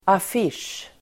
Uttal: [af'isj:]